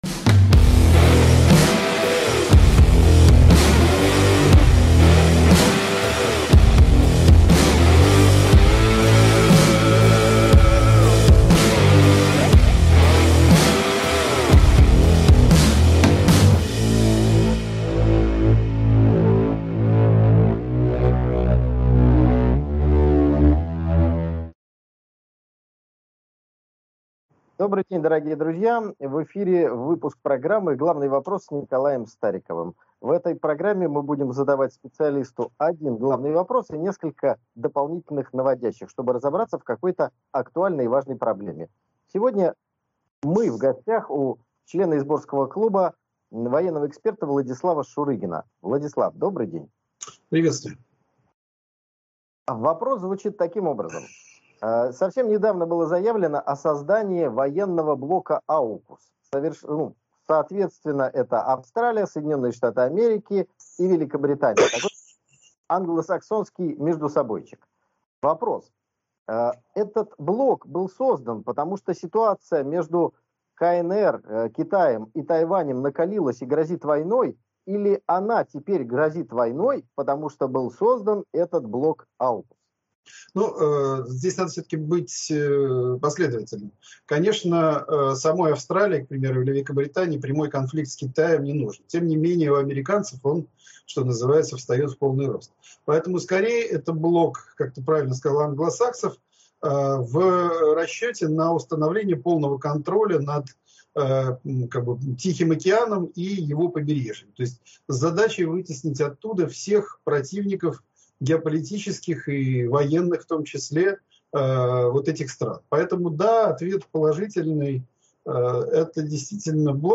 В пилотном выпуске моей новой авторской программы «Главный вопрос» гостем стал военный эксперт, коллега по «Изборскому клубу» Владислав Шурыгин.